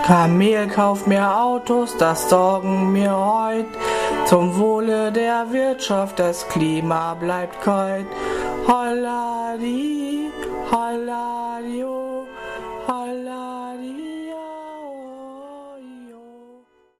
Gstanzl, Jodeln